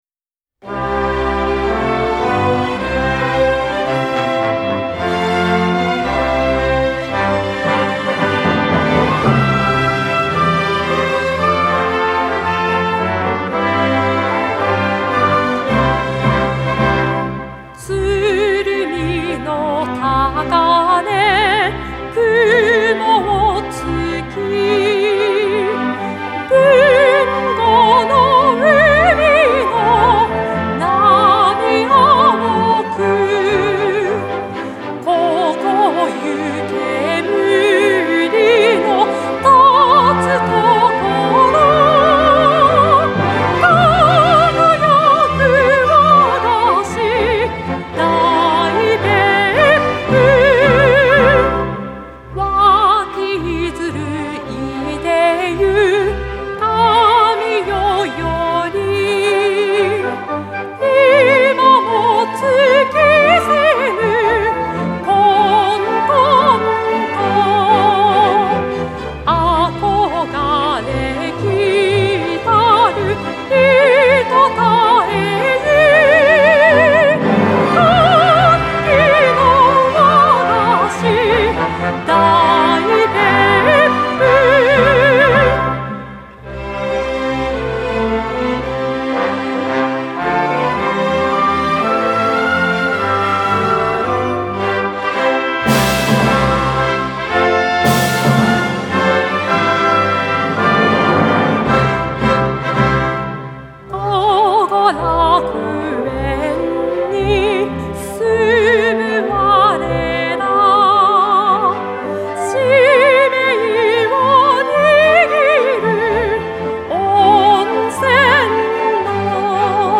1935年（昭和10年）、亀川町、朝日村、石垣村が別府市に合併したことを記念につくられました。合併によって市域が拡大し、文字どおり大別府となり、大いなる発展を遂げていく別府市を、壮快に、そして雄大に歌っています。